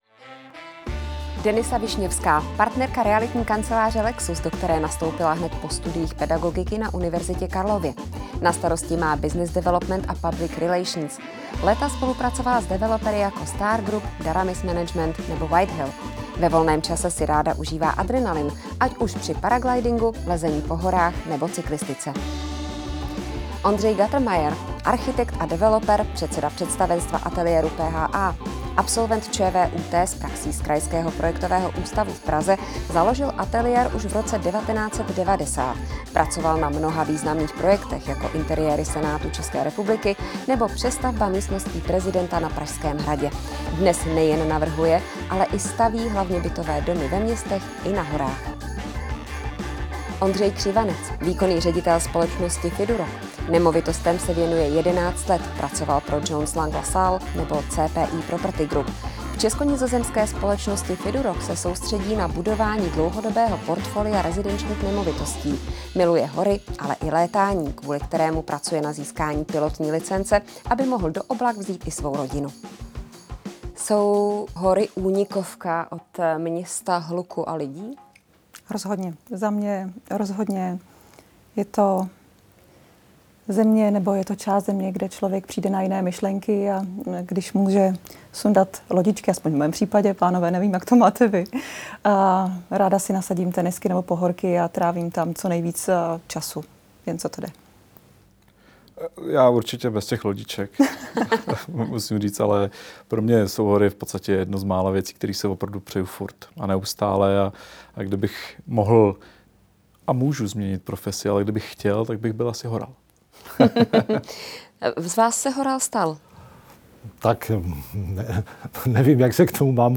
Diskuze